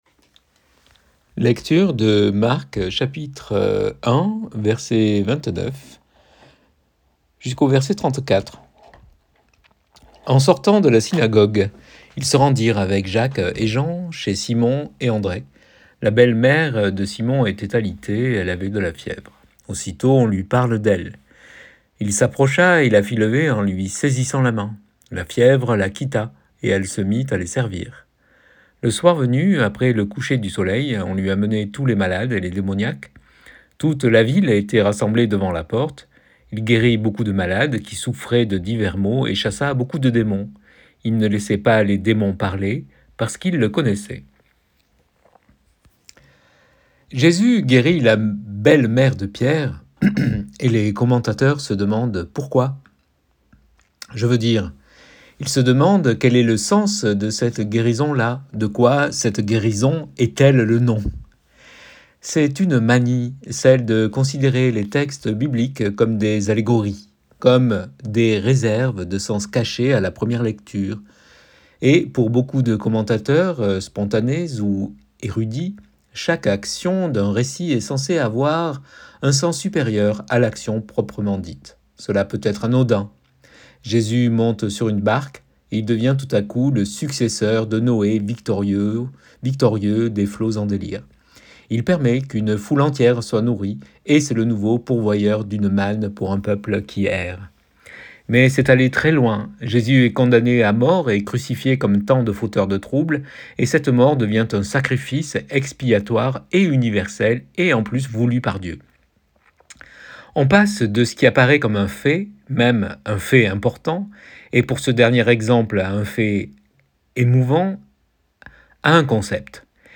Texte et prédication du 4 février 2024.mp3 (23.14 Mo) PRÉDICATION DU 4 FÉVRIER 2024.pdf (86.64 Ko)